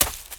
High Quality Footsteps
STEPS Leaves, Run 19.wav